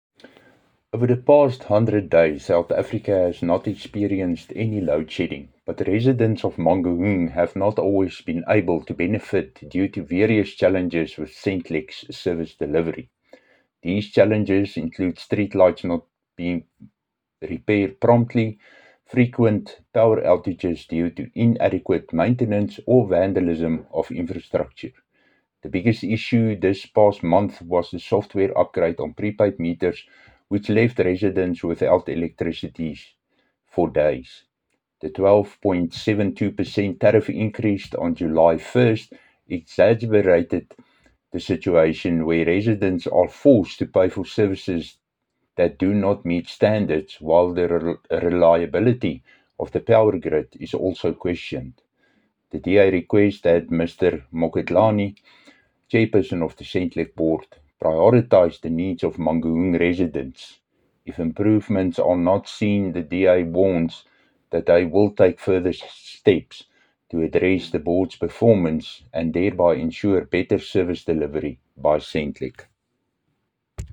Afrikaans soundbites by Cllr Dirk Kotze